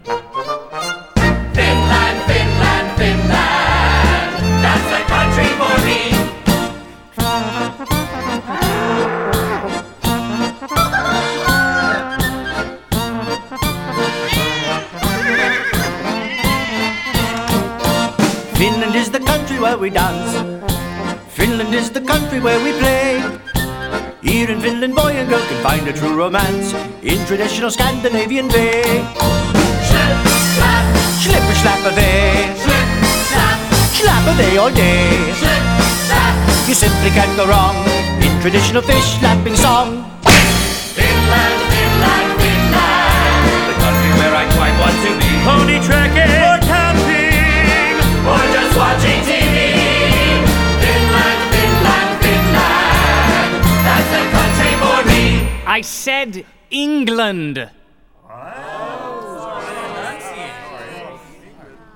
Genre: Musical.